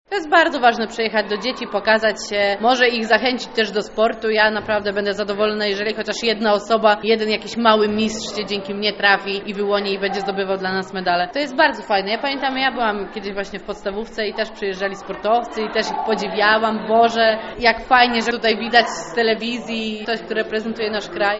Jak przyznaje młociarka Malwina Kopron, takie spotkania pomagają promować sport wśród młodzieży: